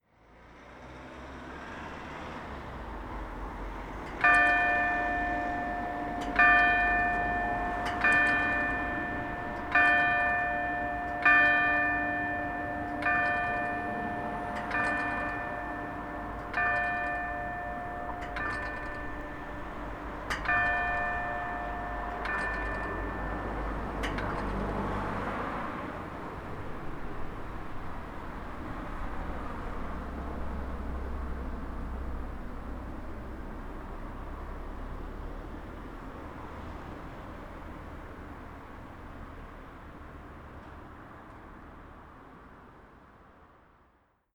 Et la cloche du Châble ?
beaumont-le-chable.mp3